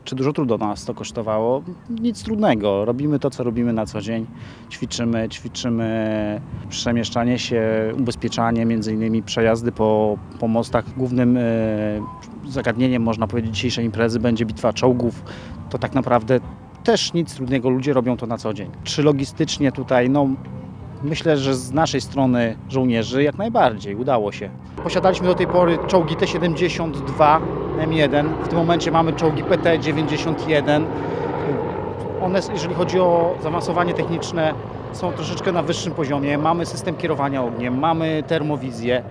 – Organizacja tego typu wydarzenia  nie jest dla nas zbyt duży problemem – mówi jeden z żołnierzy.